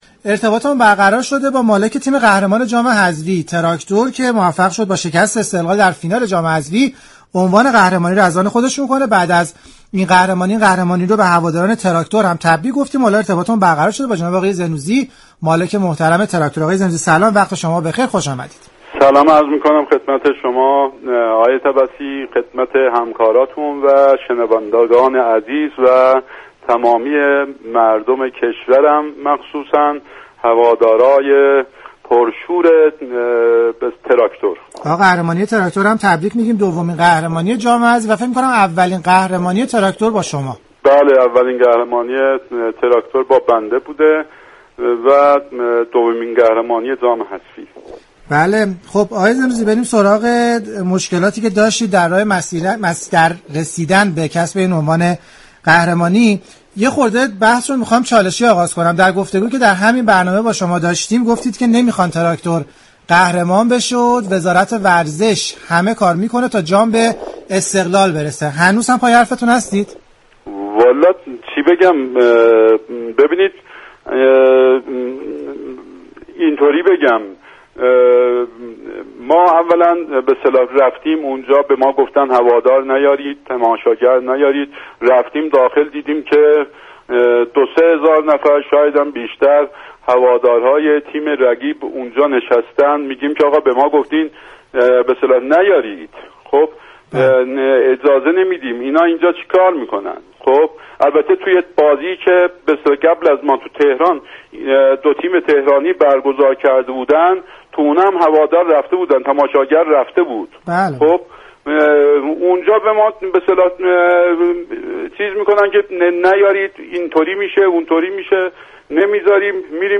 برنامه زنده "از فوتبال چه خبر؟"